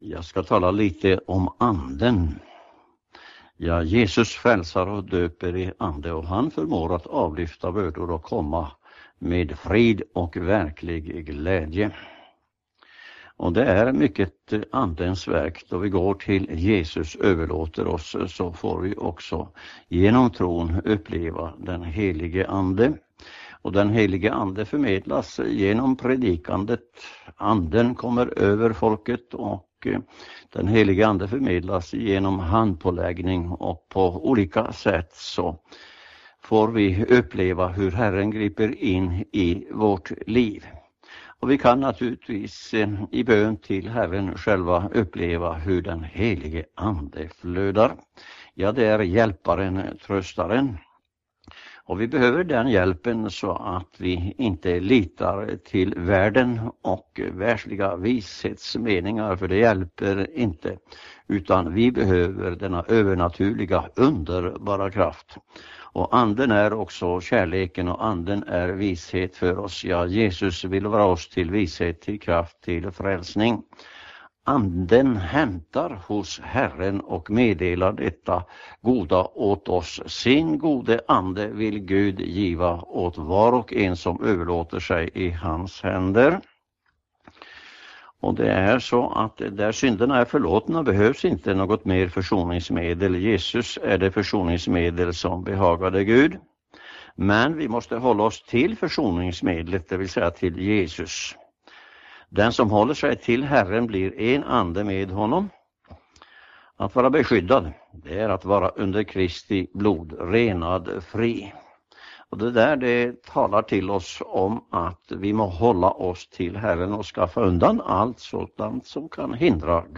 Lyss na till 15-min.-predikan: � Om dopet i helig Ande